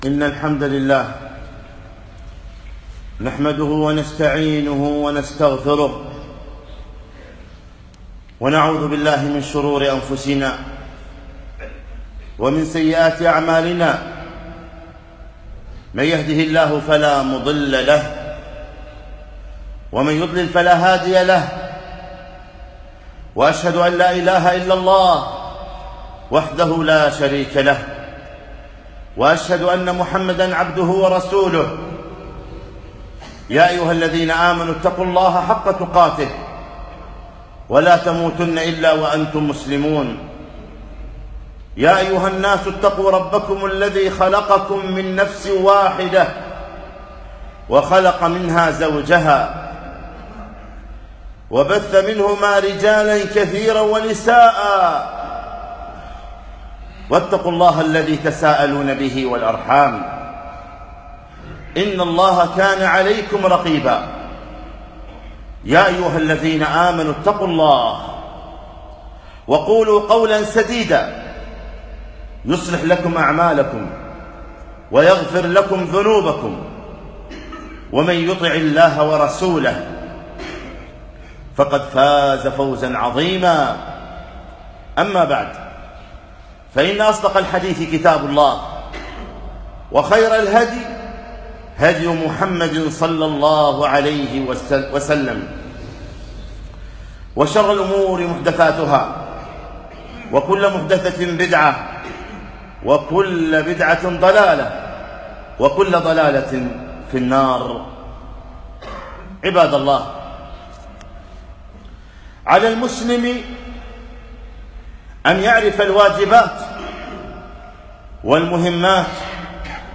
خطبة - أعطِ كل ذي حقٍ حقه